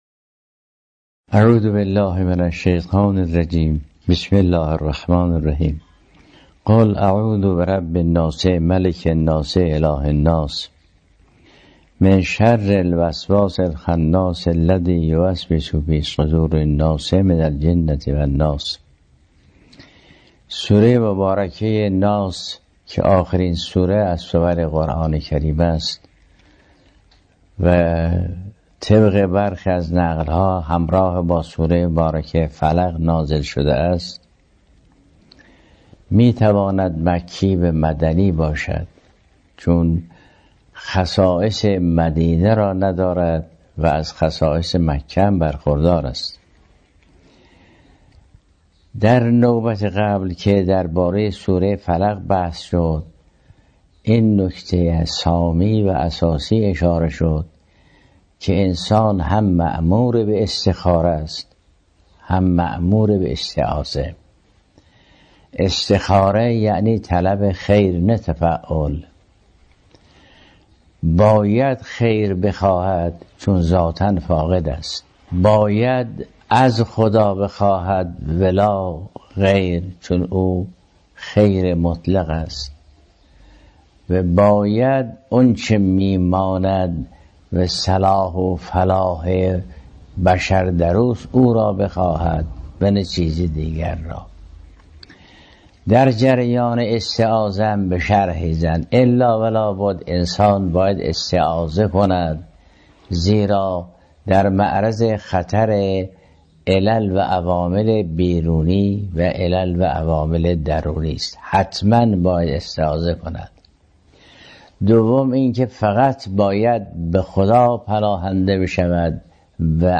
تفسیر تسنیم مفصل‌ترین تفسیر در تاریخ اسلام است که آخرین جلسات آن چندی پیش به پایان رسید. آیت‌الله العظمی جوادی آملی در آخرین جلسه تفسیر، که با اشک‌های ایشان همراه شد، به داستان شکل‌گیری این جلسه درس خارج تفسیر قرآن پرداخت.